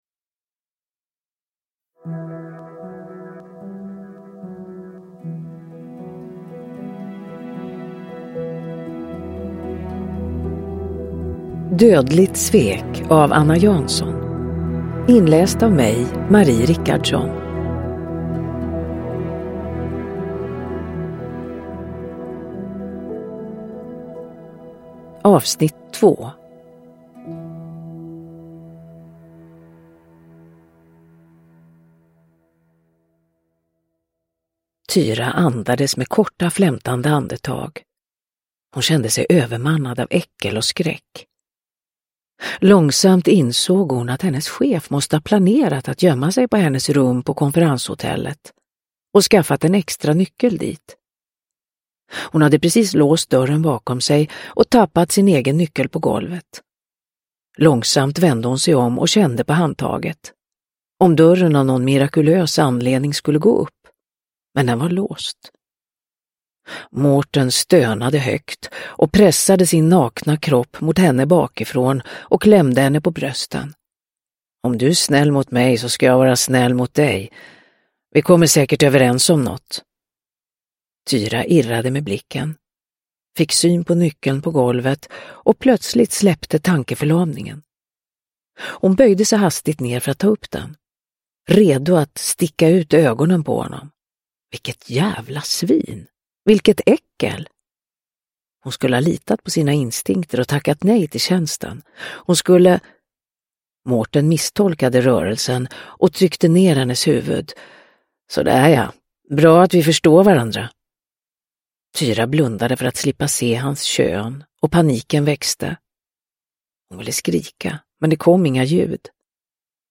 Dödligt svek - 2 – Ljudbok – Laddas ner
Uppläsare: Marie Richardson